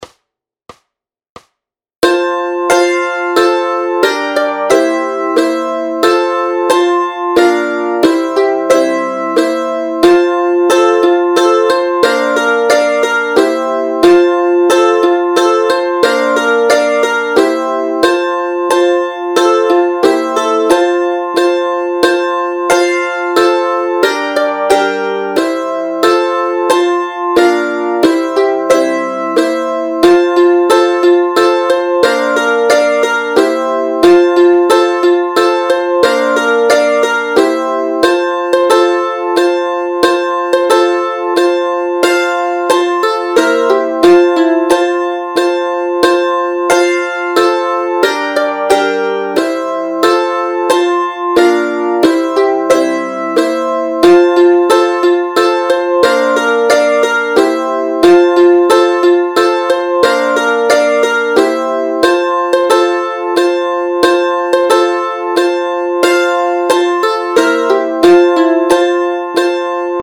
Noty, tabulatury, akordy na mandolínu.
Hudební žánr Vánoční písně, koledy